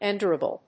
音節en・dur・a・ble 発音記号・読み方
/ɪnd(j)ˈʊ(ə)rəbl(米国英語)/